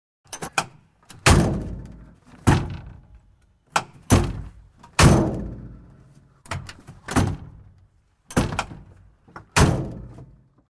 Truck Door O/C | Sneak On The Lot
Truck Door Open And Close, Close Perspective.